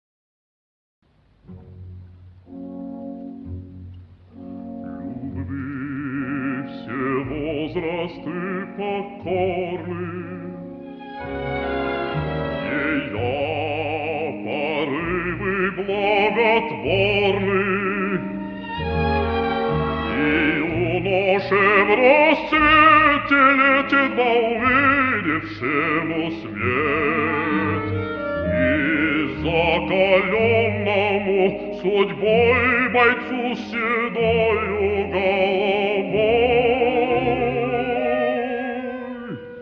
voz_baixo1.mp3